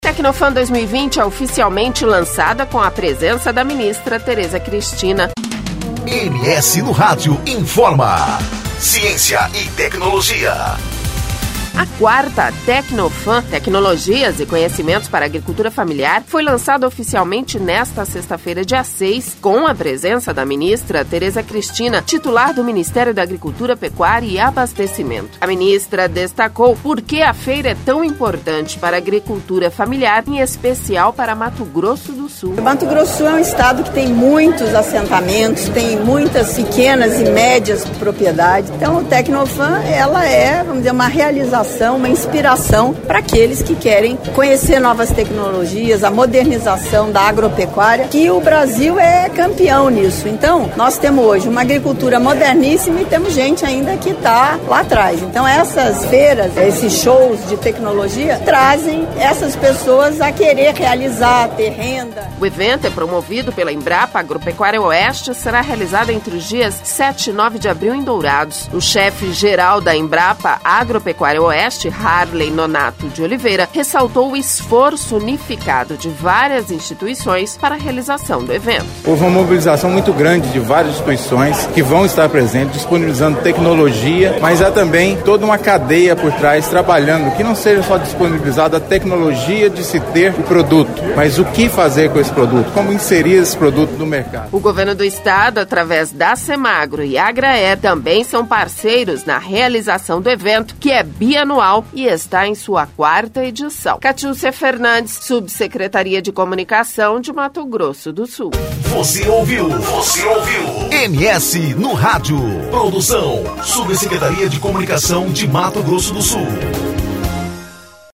A Ministra destacou porque a Feira é tão importante para a agricultura familiar, em especial para Mato Grosso do Sul.